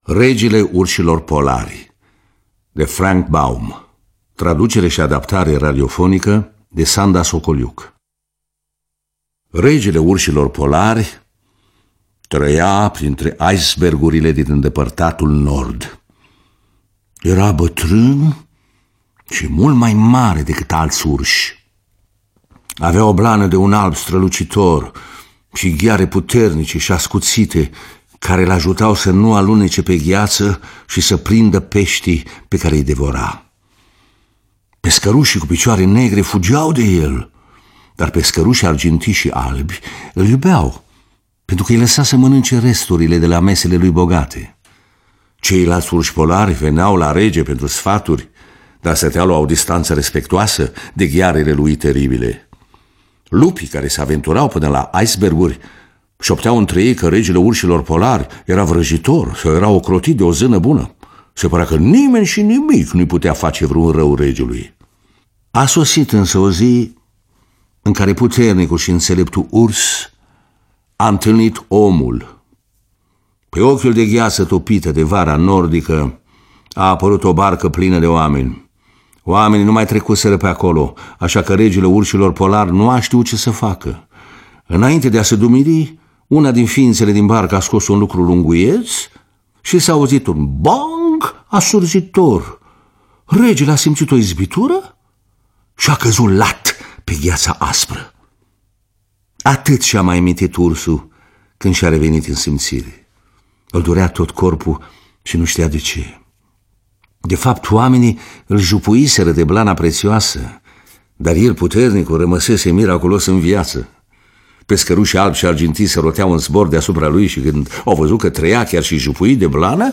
Lectura: Ion Caramitru.